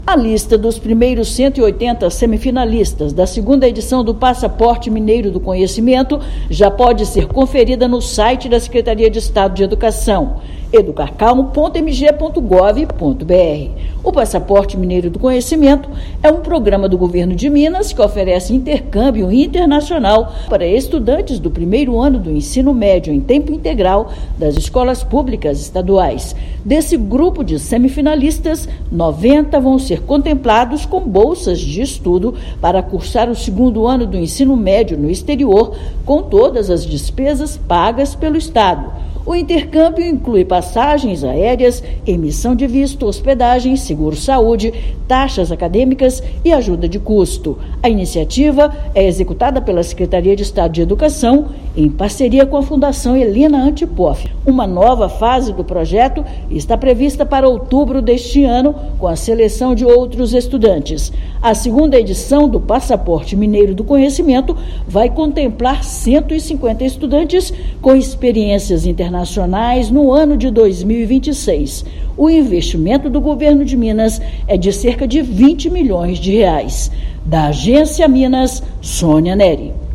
[RÁDIO] Governo de Minas divulga primeiros semifinalistas para intercâmbio do Passaporte Mineiro do Conhecimento
Os 180 estudantes classificados concorrem a 90 bolsas de estudo no exterior com despesas custeadas pelo Estado. Ouça matéria de rádio.